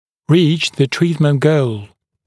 [riːʧ ðə ‘triːtmənt gəul][ри:ч зэ ‘три:тмэнт гоул]достичь цели лечения